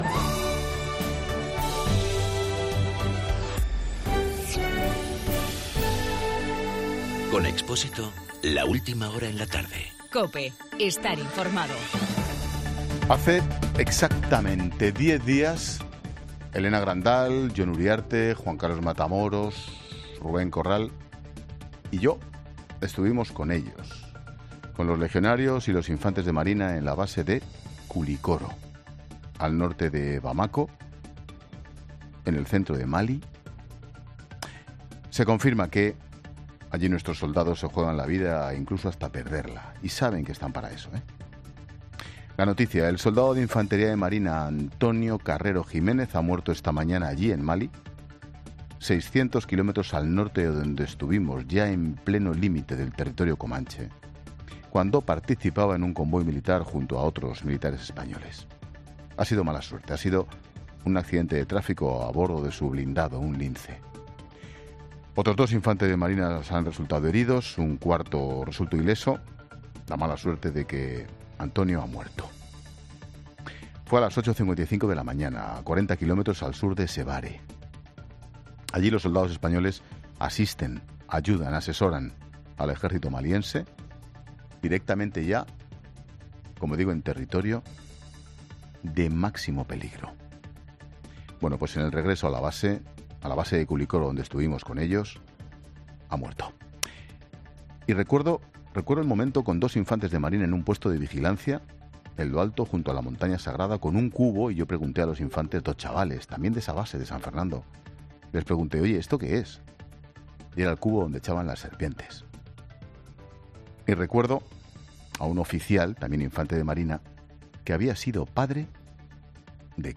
Monólogo de Expósito
El comentario de Ángel Expósito sobre el militar fallecido en Mali.